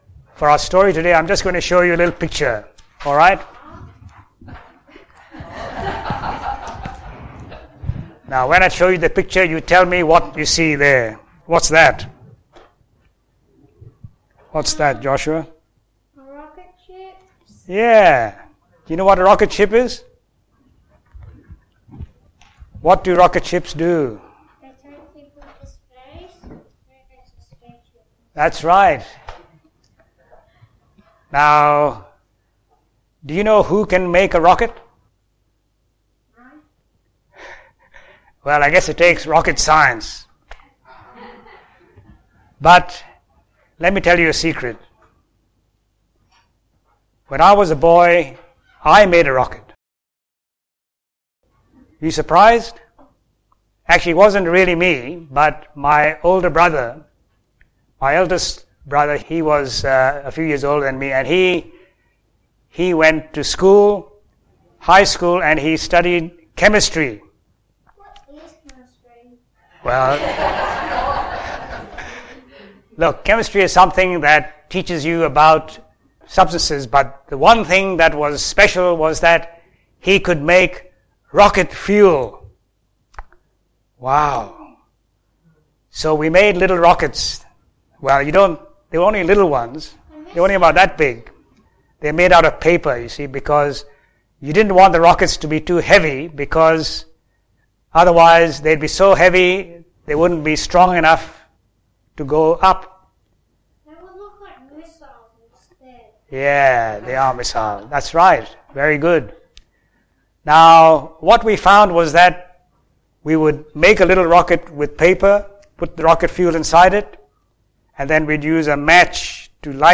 Children's Stories
SABBATH SERMONS